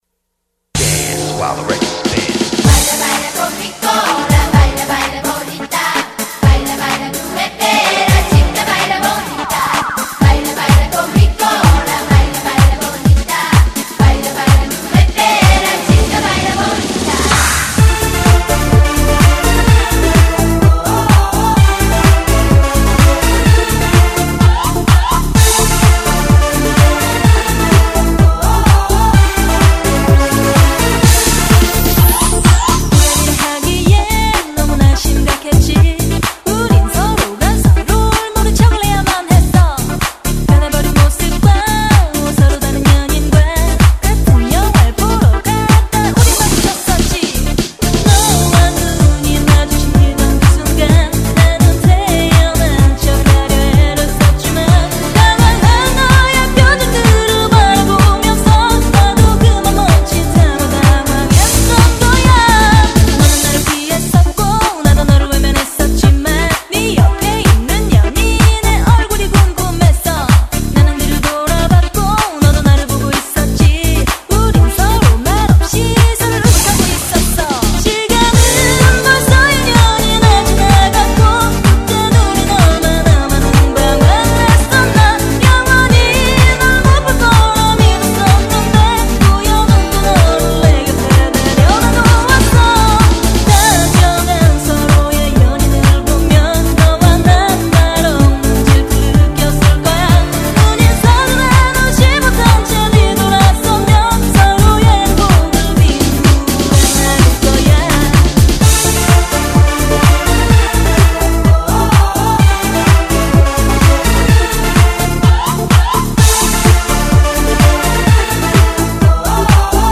BPM126--1
Audio QualityPerfect (High Quality)